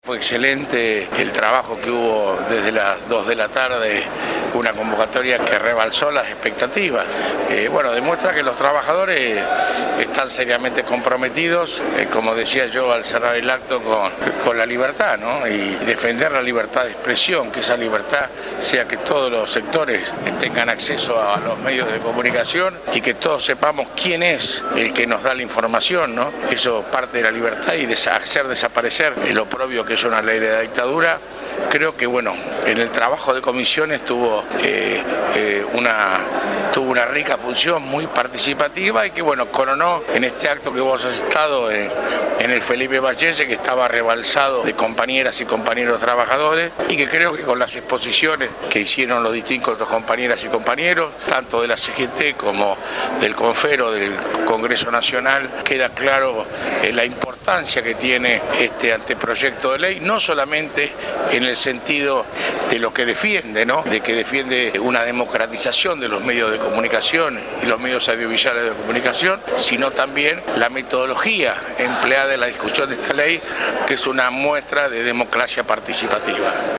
En el edificio de la CGT se llevó a cabo una jornada de apoyo a la Nueva Ley de Servicios Audivisuales que suplante la actual Ley de Radiodifusión de la dictadura militar, profundizada por Carlos Menem en 1992.
recogió audios de la charla-debate.